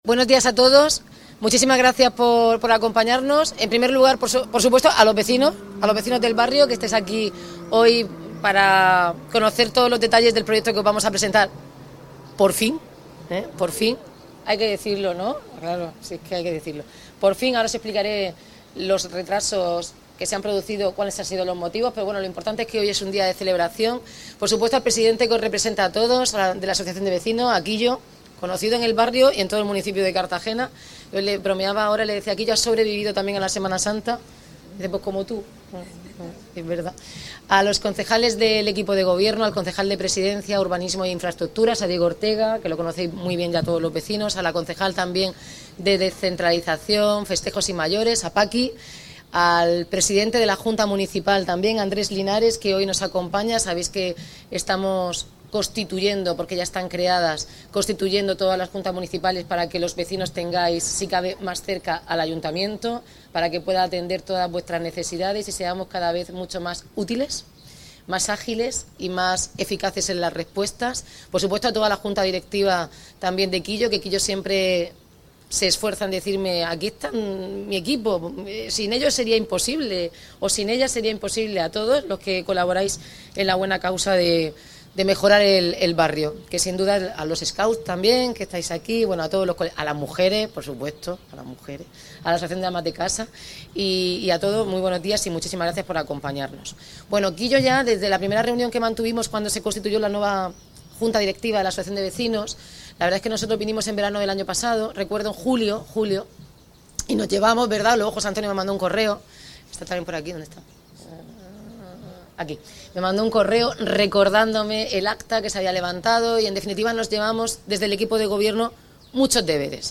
Audio: Presentaci�n del proyecto de ampliaci�n y reforma del local social de la barriada San Gin�s (MP3 - 15,59 MB)